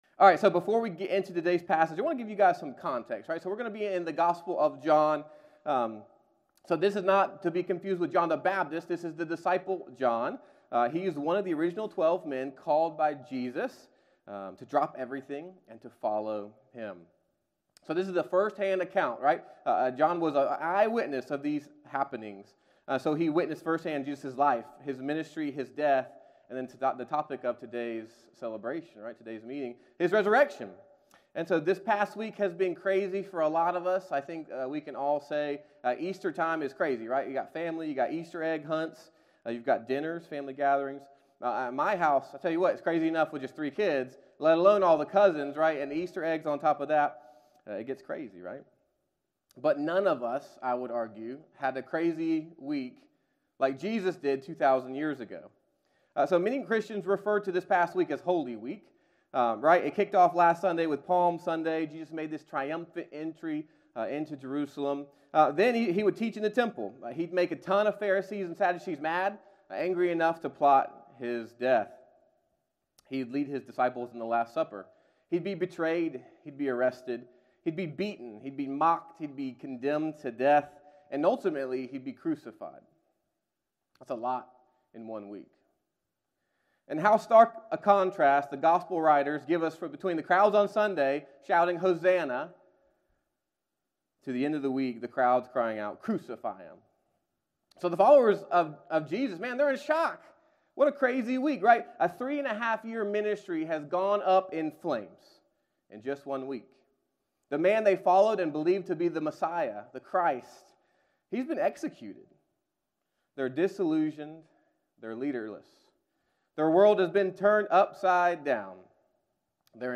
7 AM SONRise Service